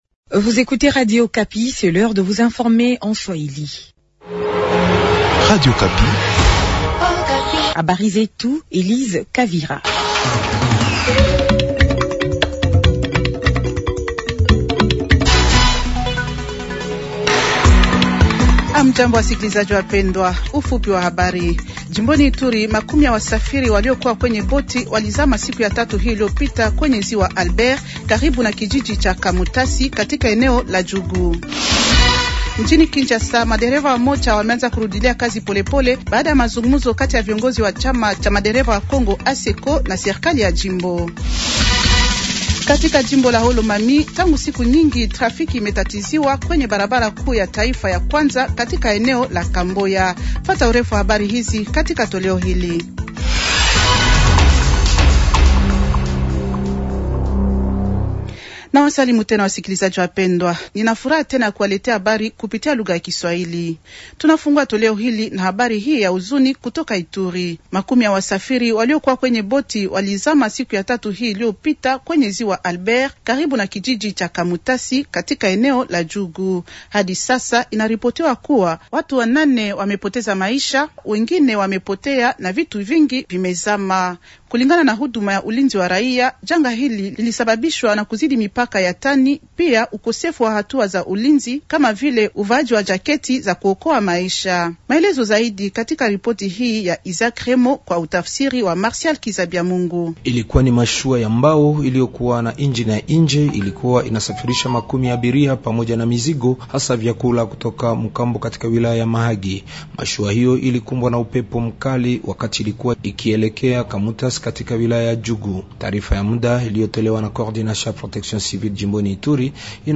Journal Swahili vendredi matin 200326